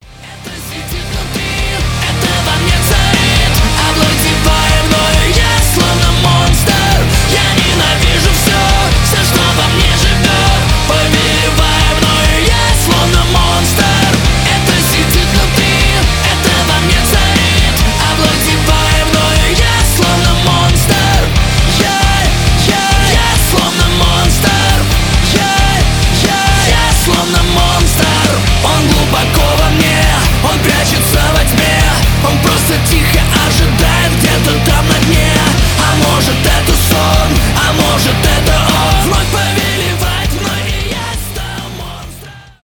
громкие
cover
тяжелый рок